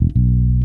Bass_78.wav